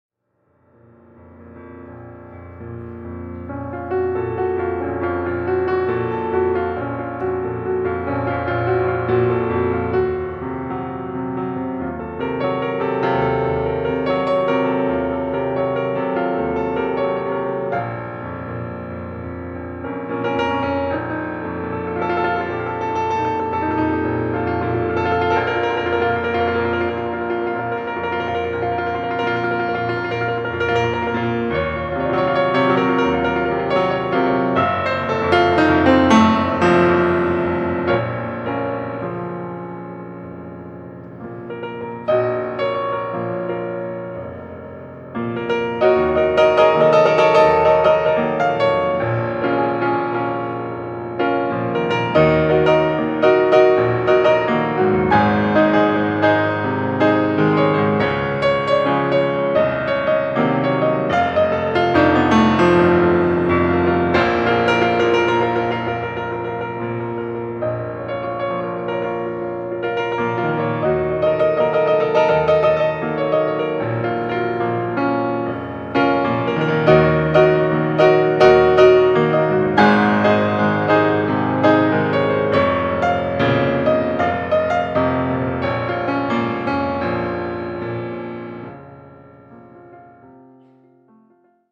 improvisation Jazz